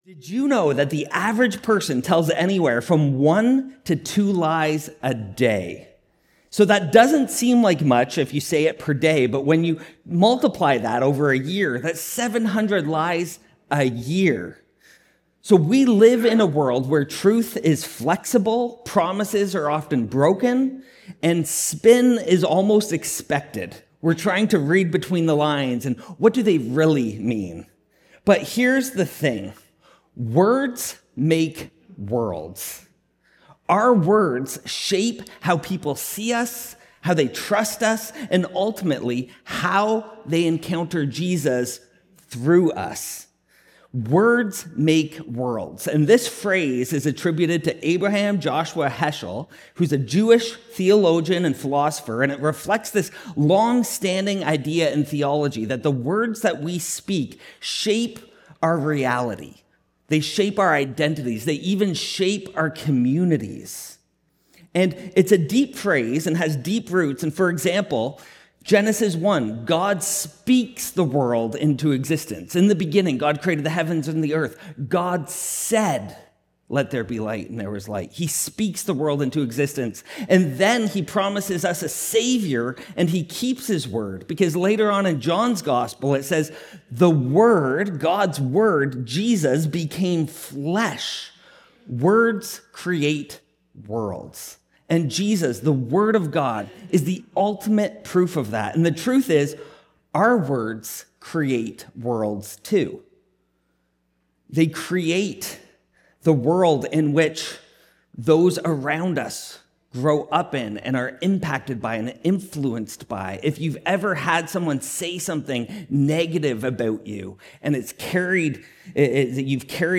What we say matters more than we think. In a world filled with spin, half-truths, and broken promises, Jesus calls us back to something simple—and powerful: Let your yes be yes, and your no, no. In this message, we explore how our words shape trust, build relationships, and even influence how others encounter Jesus.